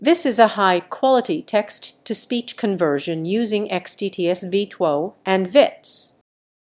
output_vits_gpu.wav